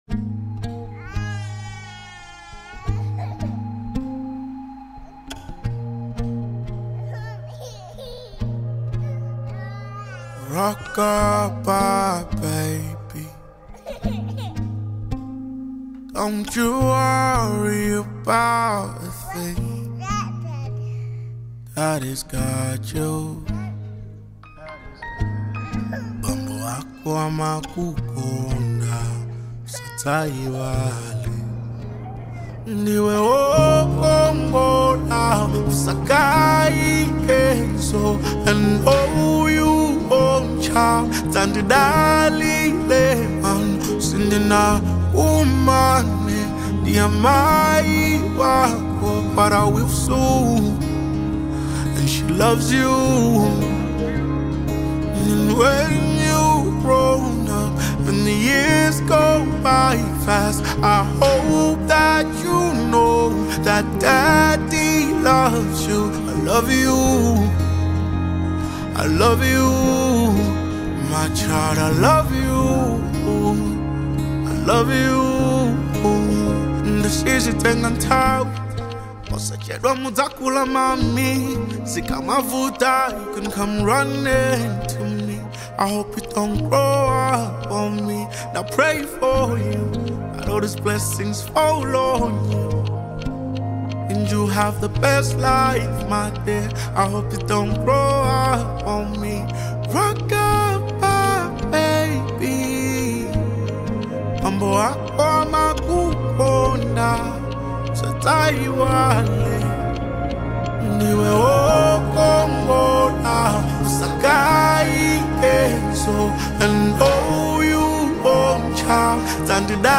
tender and heartfelt track
With gentle melodies and sincere vocals
Genre : Soul